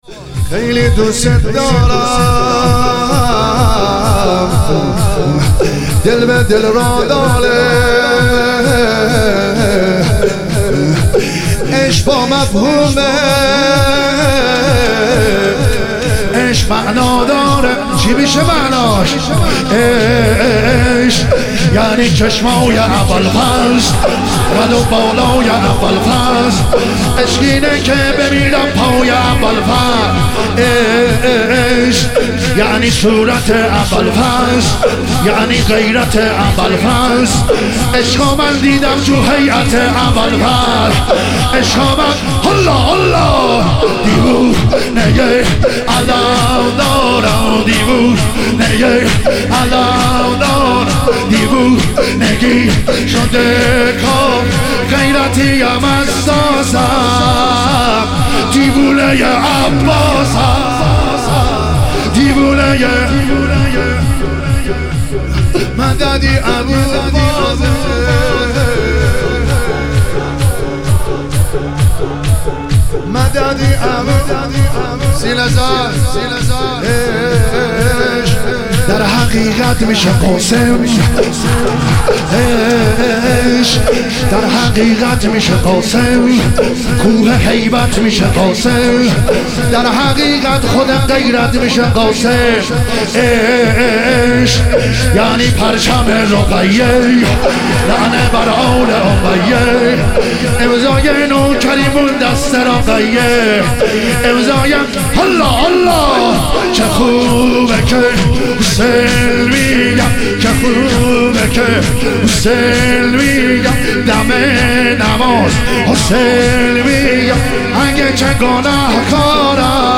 تخریب بقیع - شور